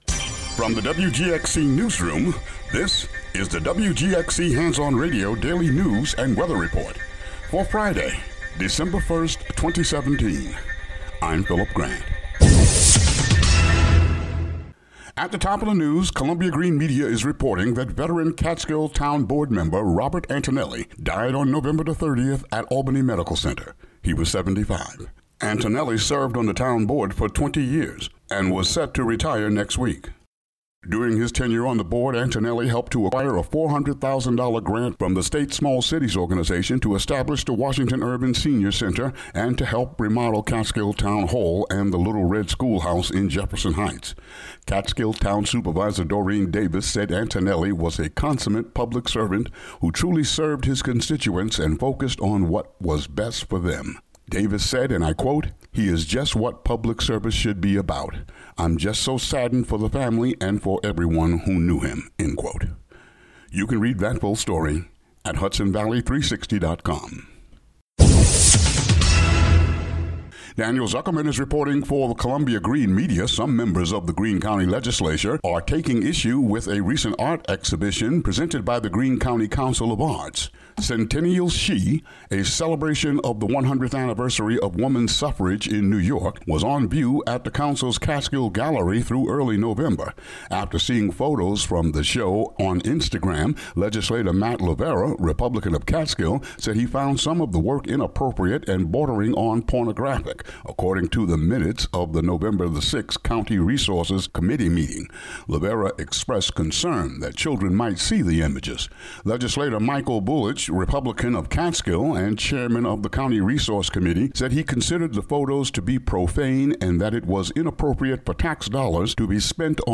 Daily local news for Fri., Dec. 1.